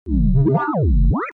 Déplacement.mp3